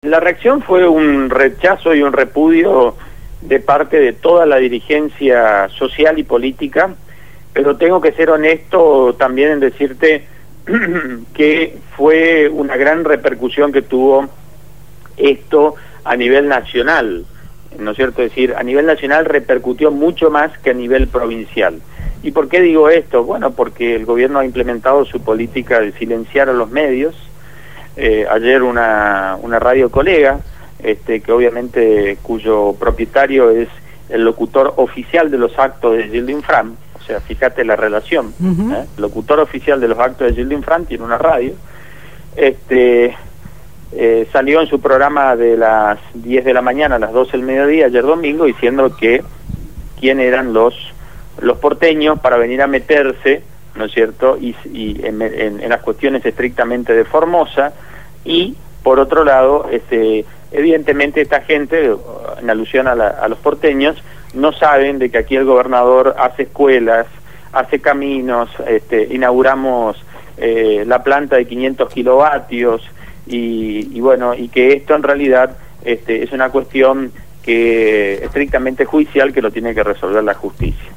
entrevistaron